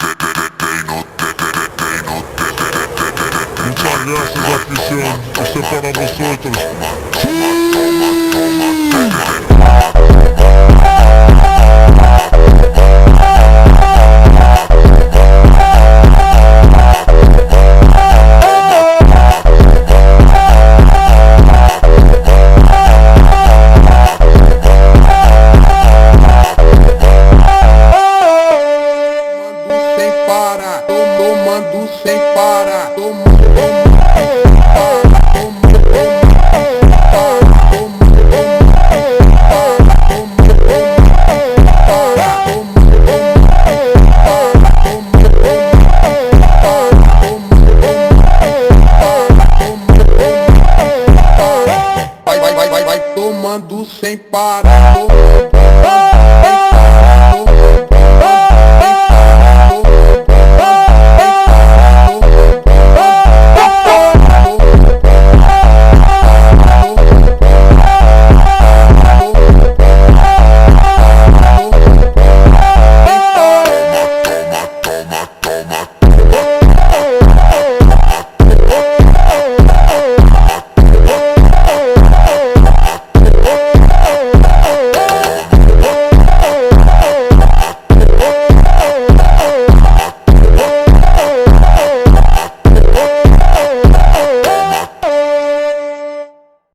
Зарубежная